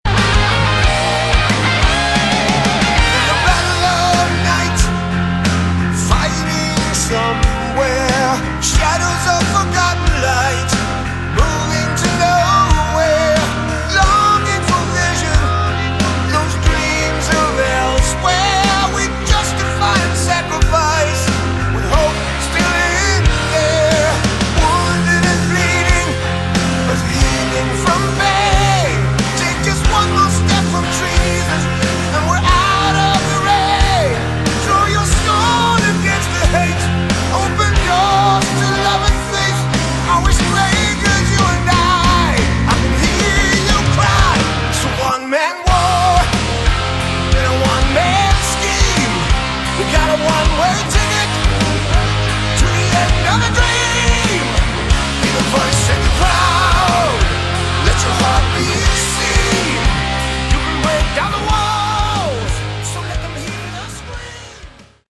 Category: Hard Rock
vocals
guitars
bass
keyboards
drums